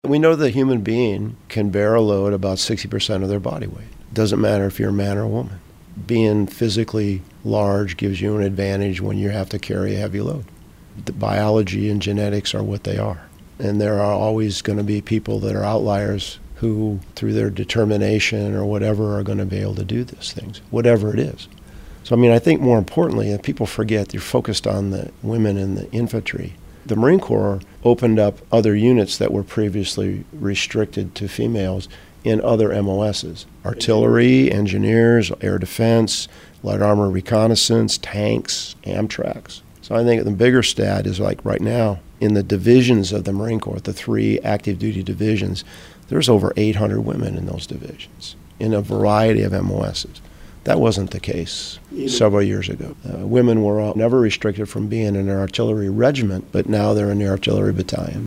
Gen. Robert Neller will step down as Marine Corps commandant this fall. In a wide-ranging interview, he talks about Russia and China, cyberwarfare, female Marines and sexual assault in the Corps.